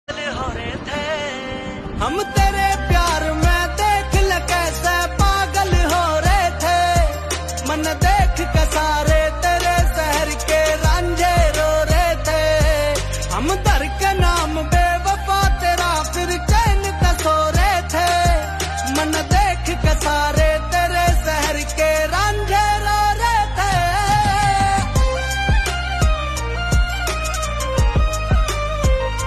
Haryanvi Songs
• Simple and Lofi sound
• Crisp and clear sound